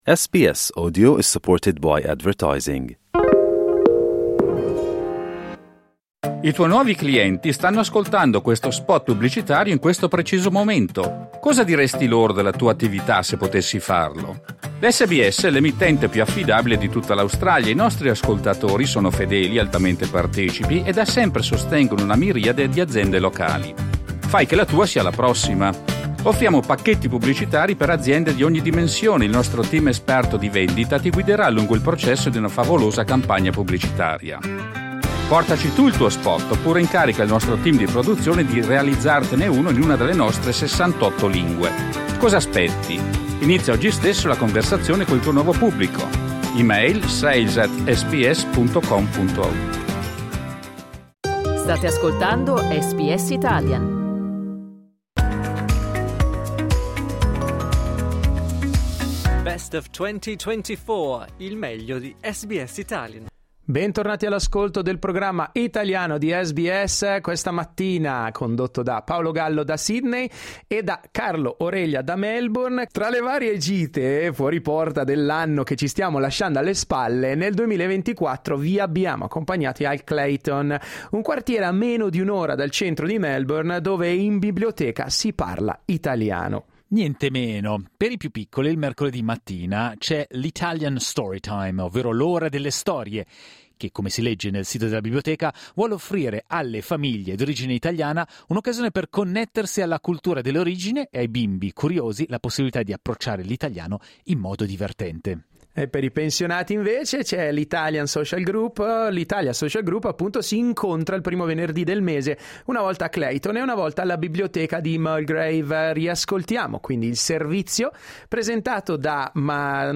Dai nostri archivi, un servizio dedicato alle attività in italiano organizzate dalle biblioteche di Clayton e Mulgrave.